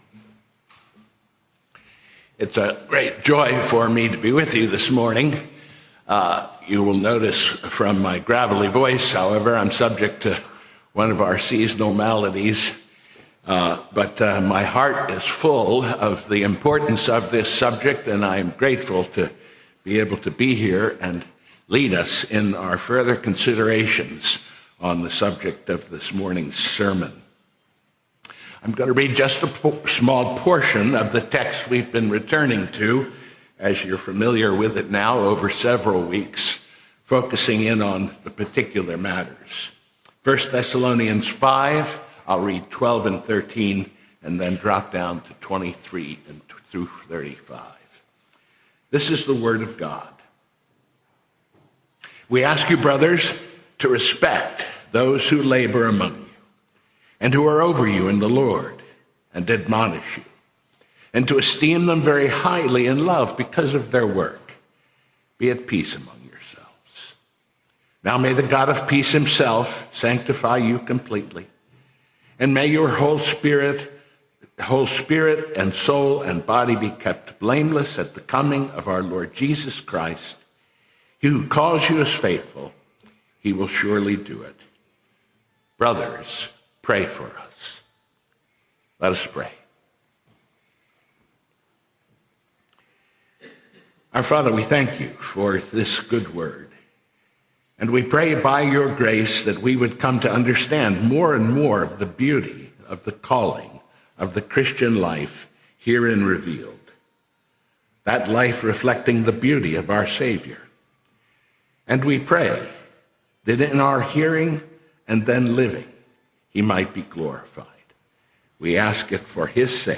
Pray for Ministers of the Gospel, Part 3: Sermon on 1Thessalonians 5:12-28 - New Hope Presbyterian Church